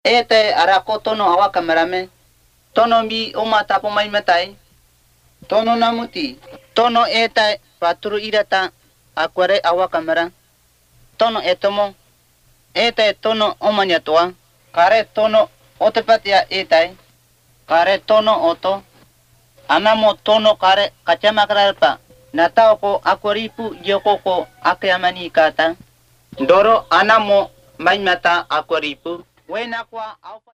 Most use a storytelling approach. These are recorded by mother-tongue speakers